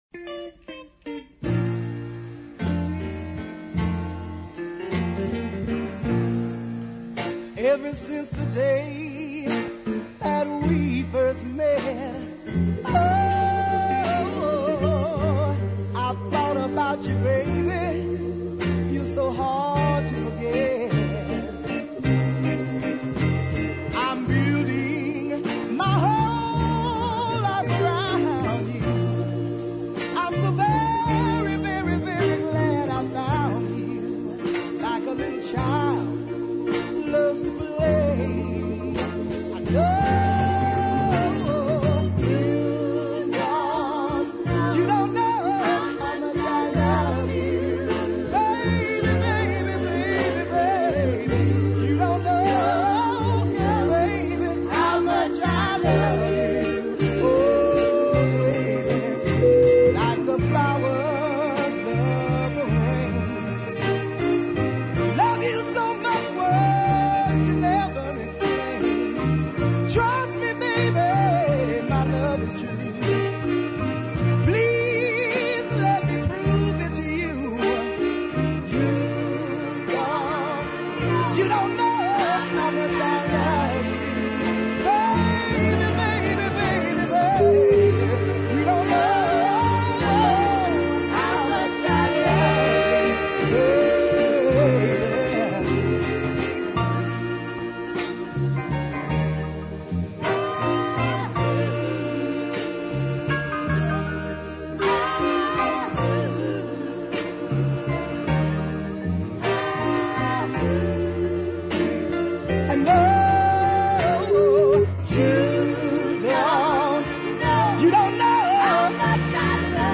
West Coast pianist and singer